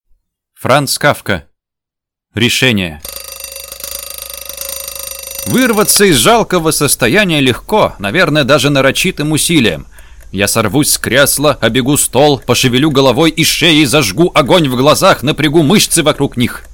Аудиокнига Решения | Библиотека аудиокниг